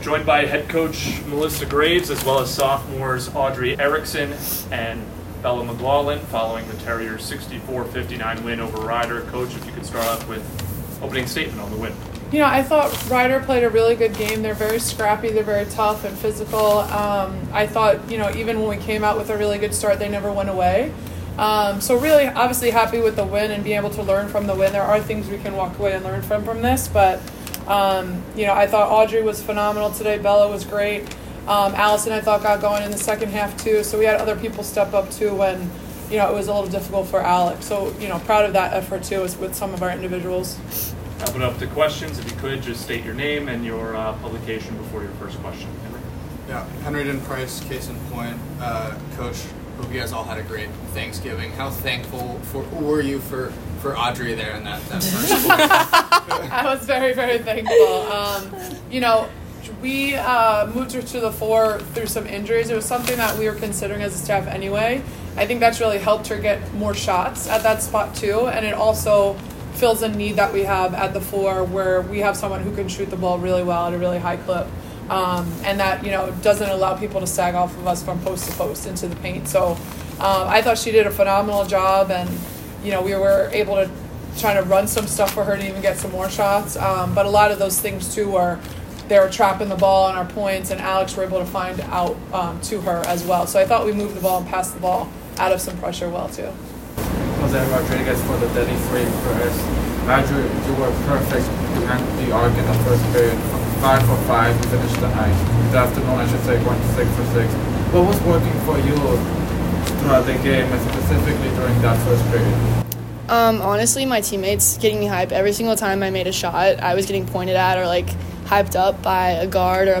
WBB_Rider_Postgame.mp3